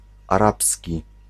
Ääntäminen
IPA: /a.ʁab/